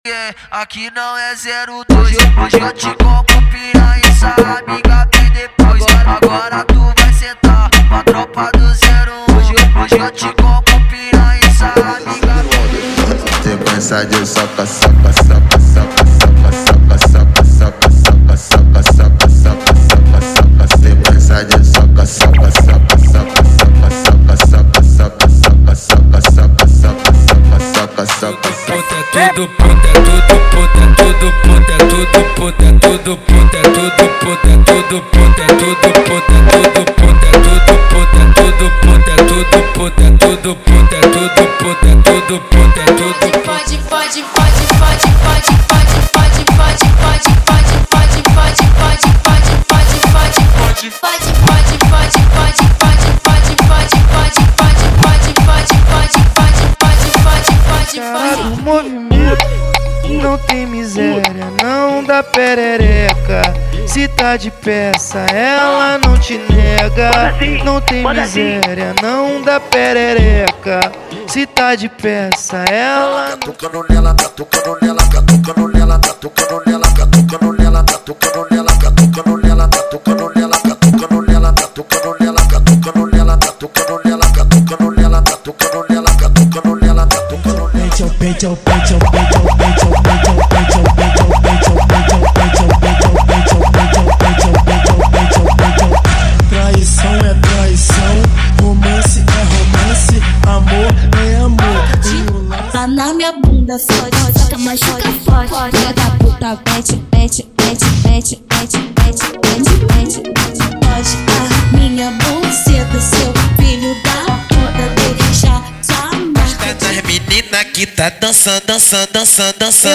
• Funk Proibidão, Funk Rave e Funk Mandelão = 100 Músicas
• Sem Vinhetas